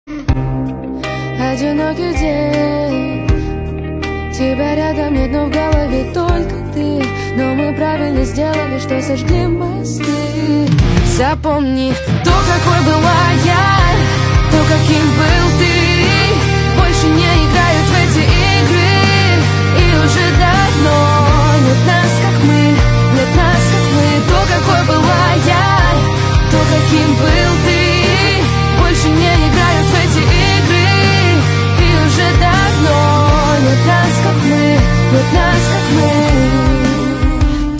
• Жанр: Русская музыка